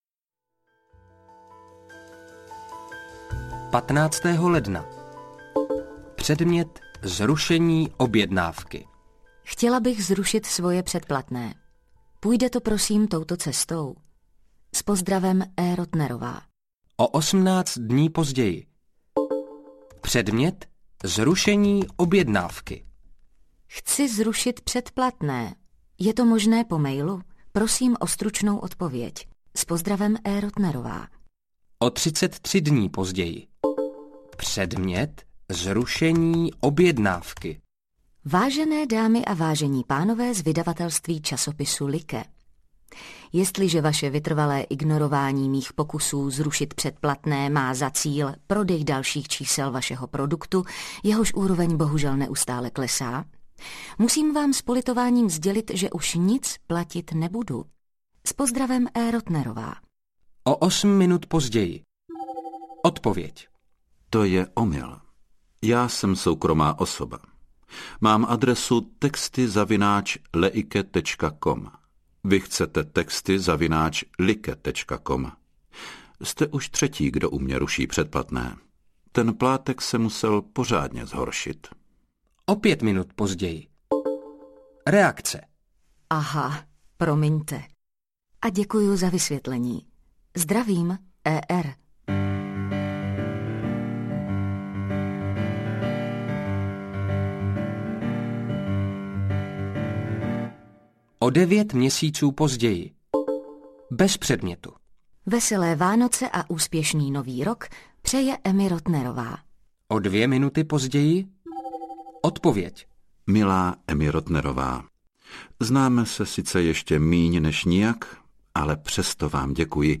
Dobrý proti severáku audiokniha
Ukázka z knihy